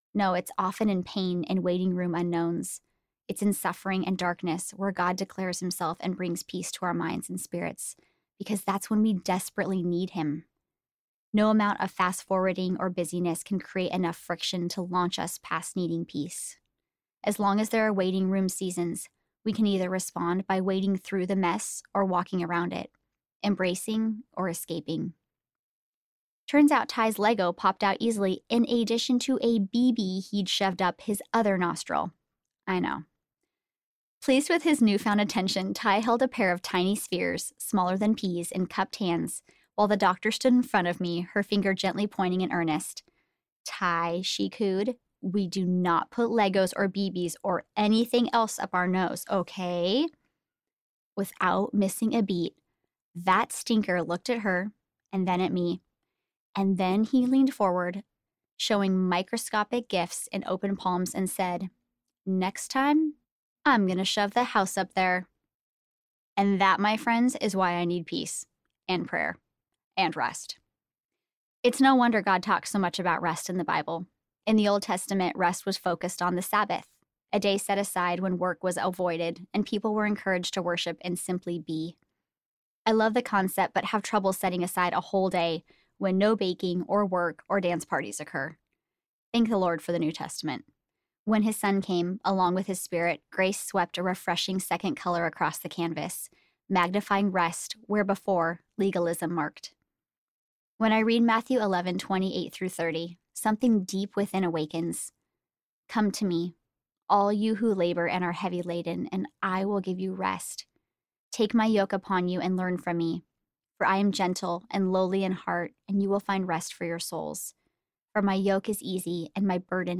Choosing Real Audiobook